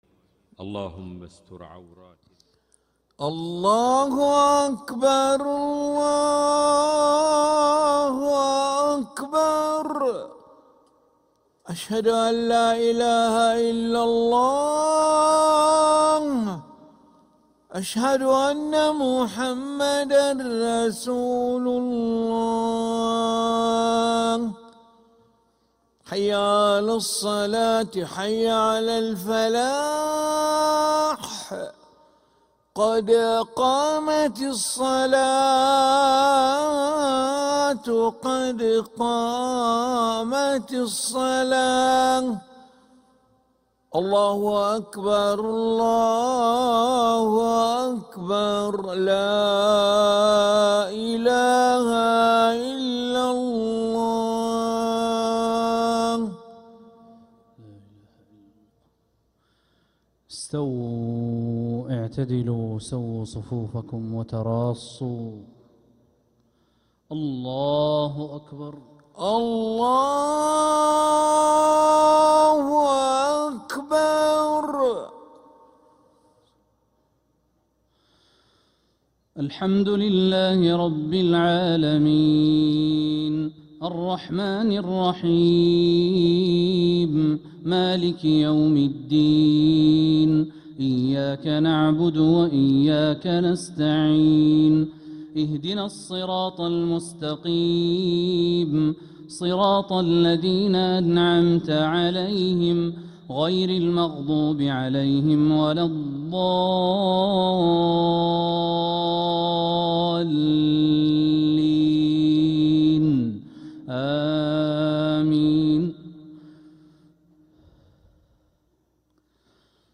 Makkah Isha - 12th April 2026